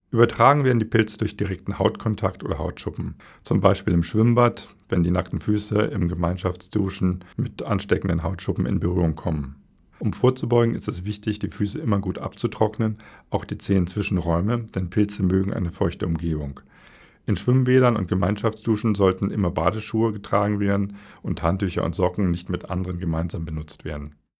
O-Töne 10.01.2024